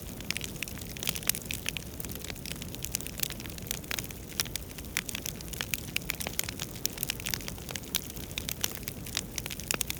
Torch Loop.ogg